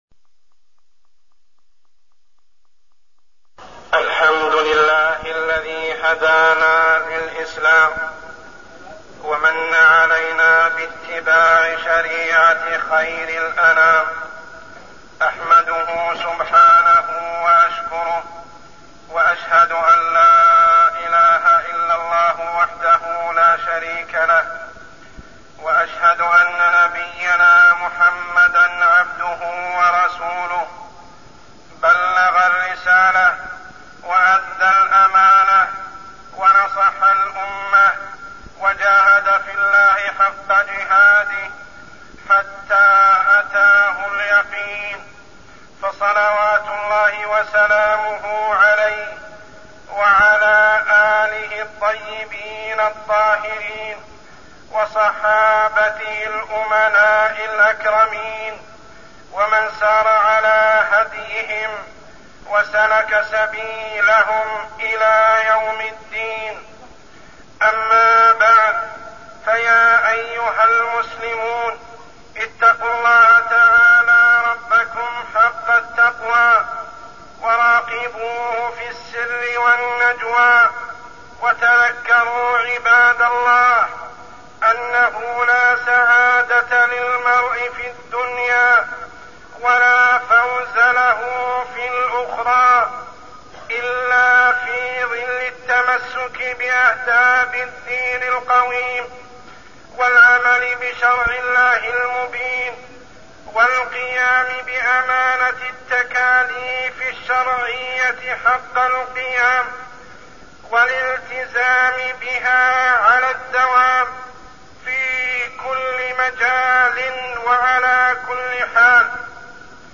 تاريخ النشر ٢٩ جمادى الآخرة ١٤١٥ هـ المكان: المسجد الحرام الشيخ: عمر السبيل عمر السبيل الأمانة